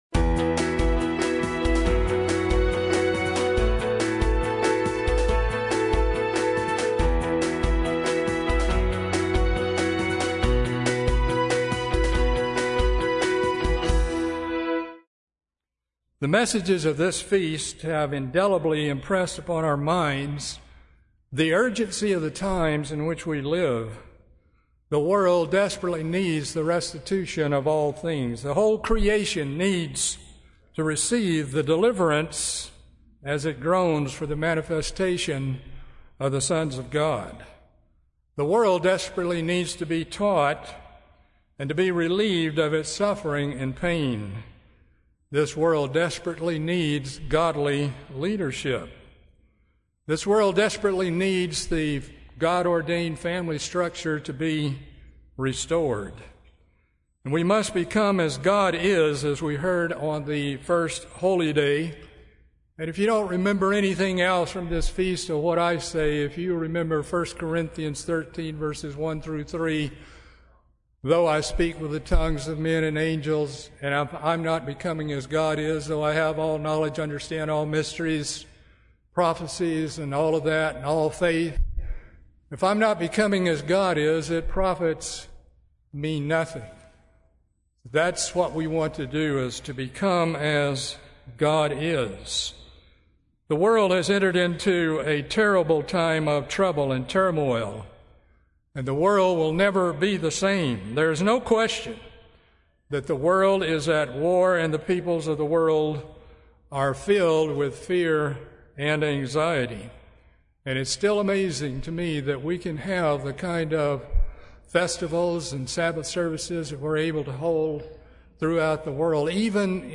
This sermon was given at the Panama City Beach, Florida 2013 Feast site.